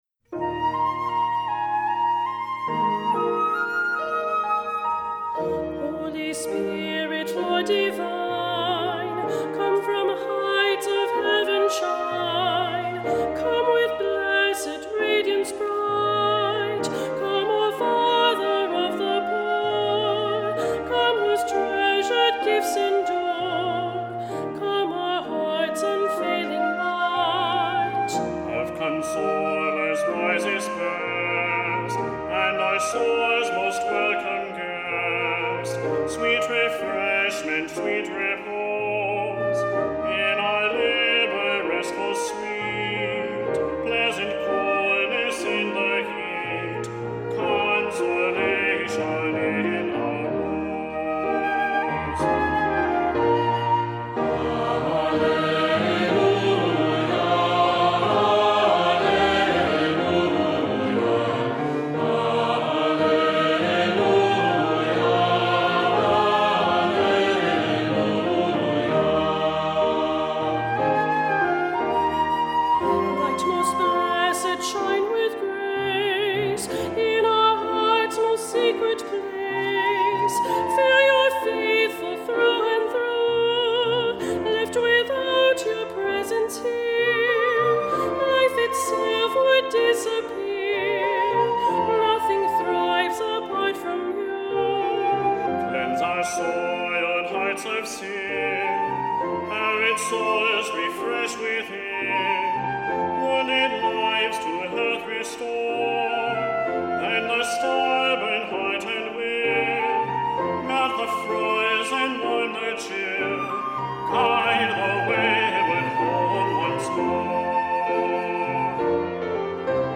Voicing: Unison; Cantor; Assembly